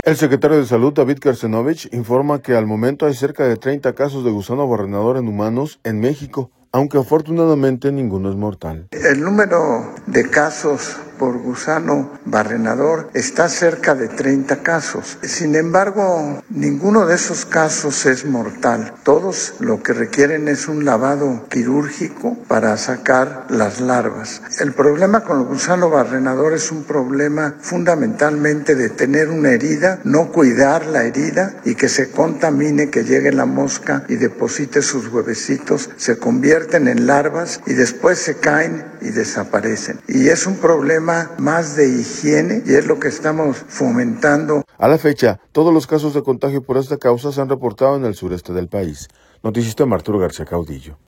El secretario de Salud, David Kershenobich, informa que al momento hay cerca de 30 casos de gusano barrenador en humanos, en México, aunque afortunadamente ninguno es mortal.